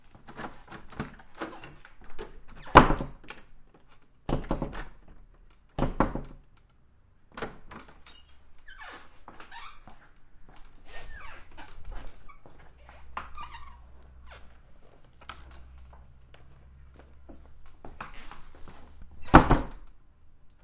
随机的 " 门木室内橱柜的吱吱作响的关闭的空间
描述：门木内部橱柜吱吱作响的吱吱声关闭roomy.flac
Tag: 室内 木材 橱柜 摇摇欲坠 吱吱响 柜子